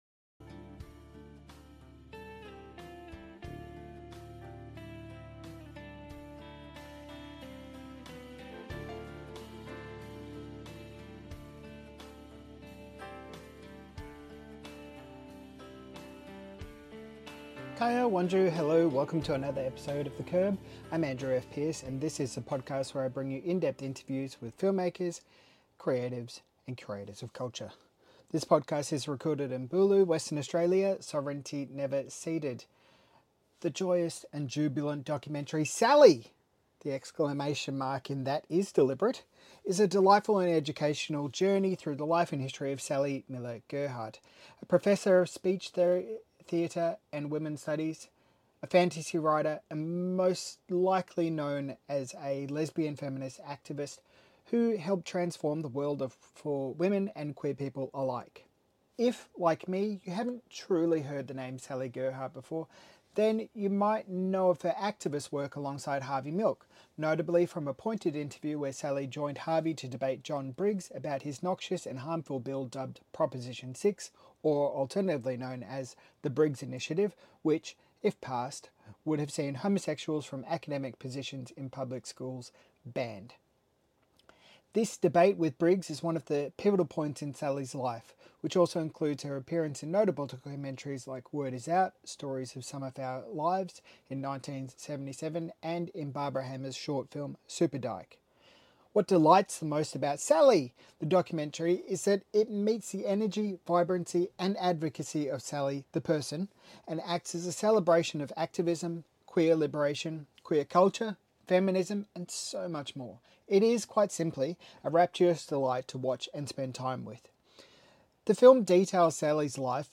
Queer Screen Interview: Sally!